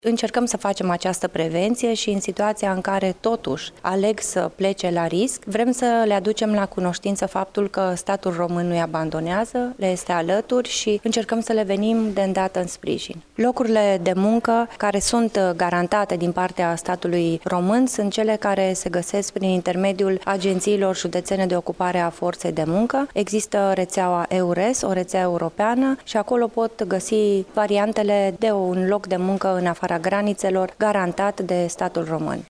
Afirmaţia a fost făcută astăzi, la Iaşi, de ministrul pentru Românii de Pretutindeni, Natalia Intotero.
Ministrul pentru Românii de Pretutindeni, Natalia Intotero a amintit de reţeaua EURES, prin care cetăţenii îşi pot găsi un loc de muncă legal, în ţări ale Uniunii Europene: